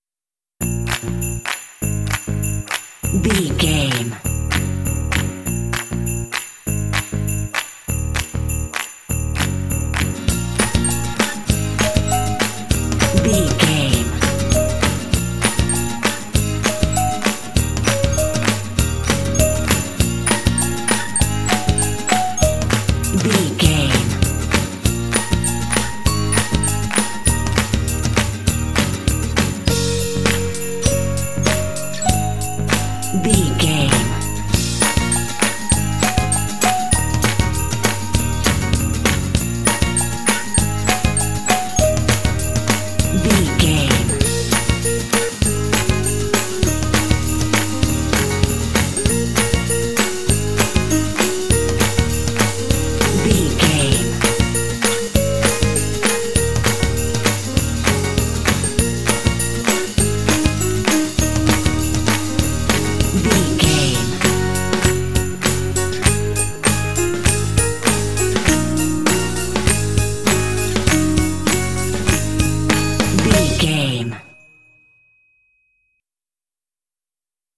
Uplifting
Aeolian/Minor
E♭
bright
piano
percussion
bass guitar
acoustic guitar
drums
electric organ
alternative rock